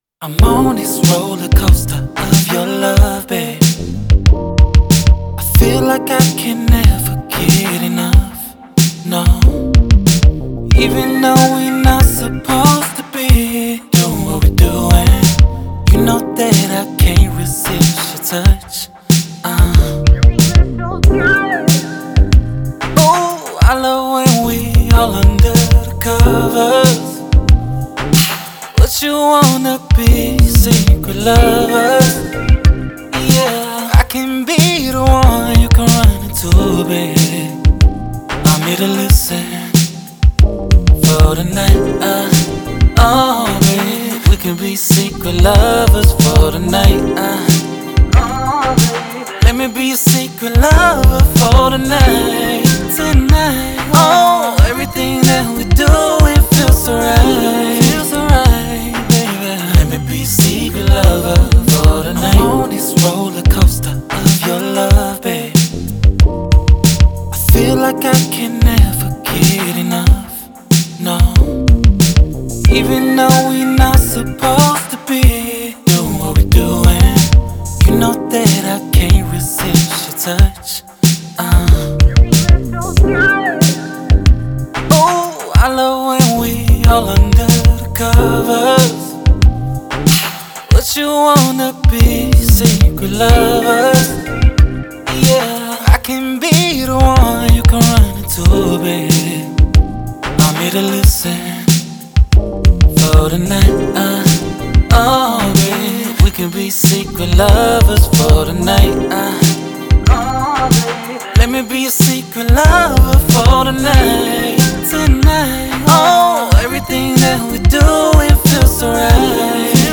R&B, 80s, 90s
C Minor